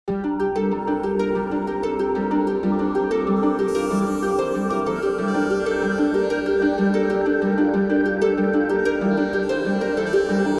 • Качество: 320, Stereo
гитара
Electronic
без слов
Downtempo